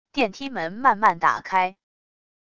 电梯门慢慢打开wav音频